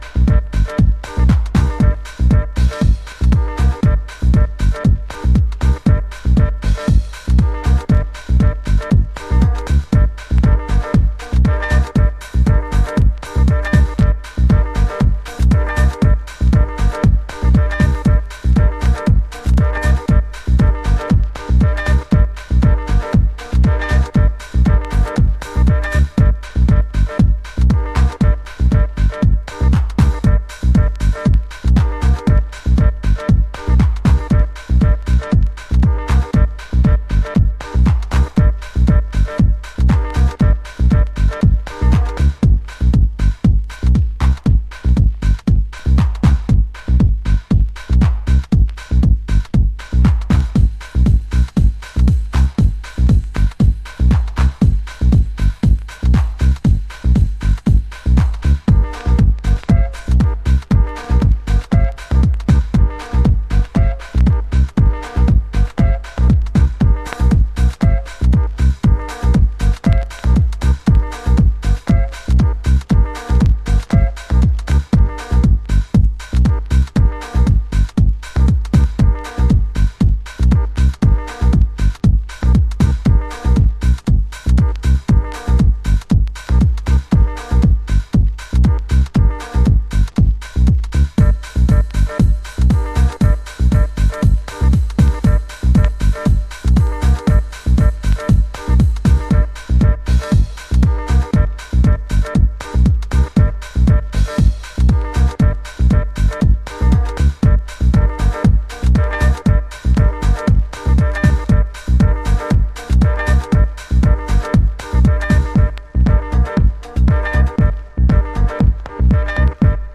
Early House / 90's Techno